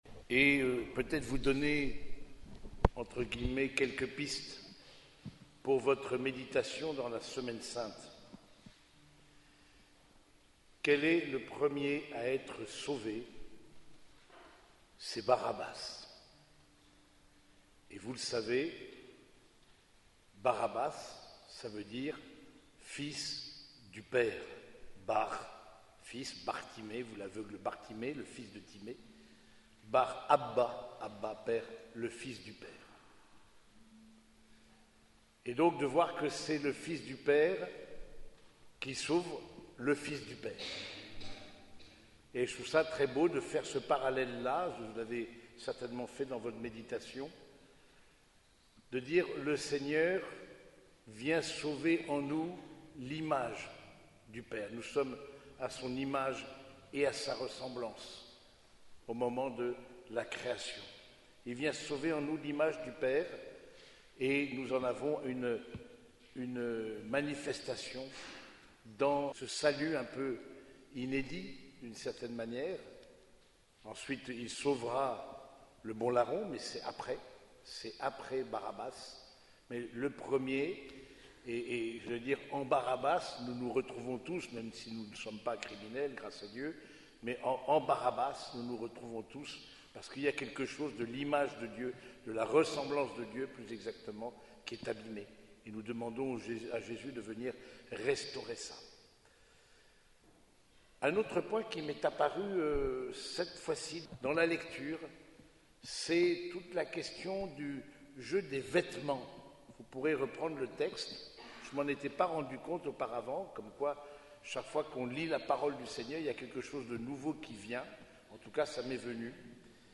Homélie du dimanche des Rameaux et de la Passion
Cette homélie a été prononcée au cours de la messe dominicale célébrée à l’église Saint-Germain de Compiègne.